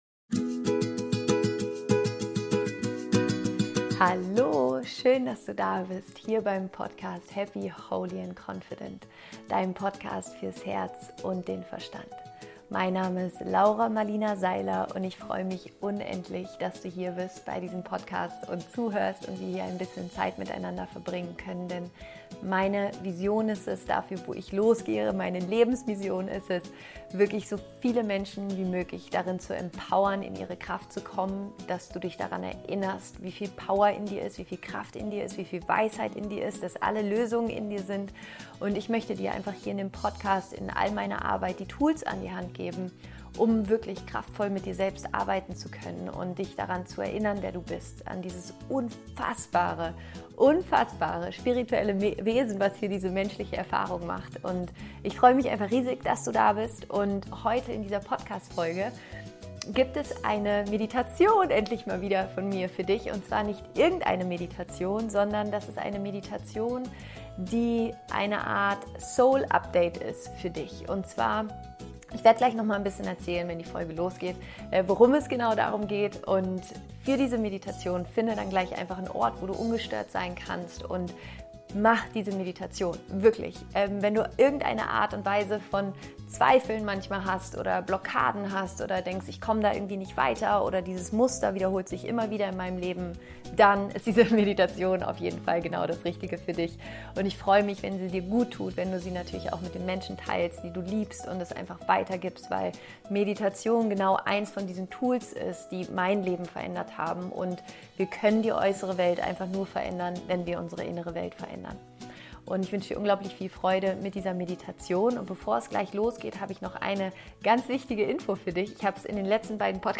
Soul Update - Meditation